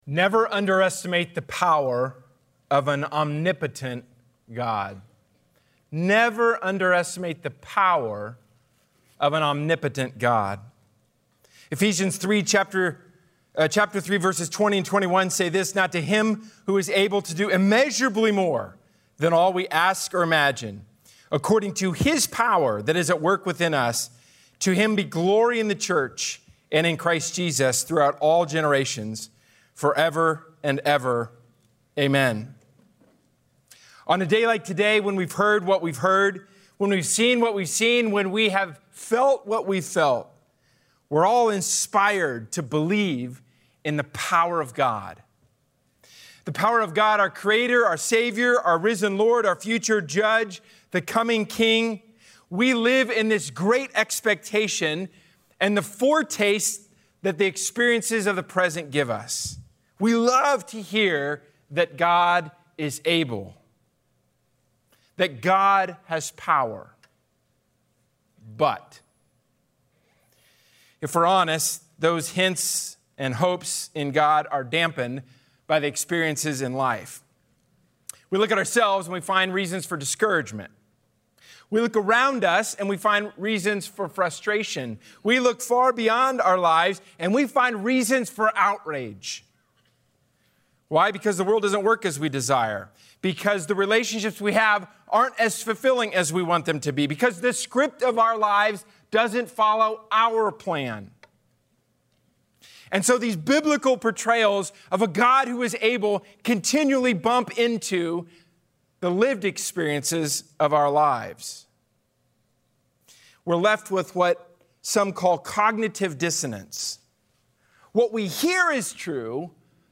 A sermon from the series "Standalone Sermons."